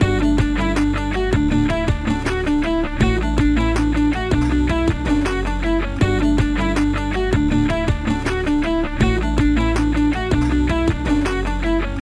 It decreases the bit depth and sample rate of your audio signal, creating a characteristic lo-fi or distorted sound.
Bitcrusher_after.mp3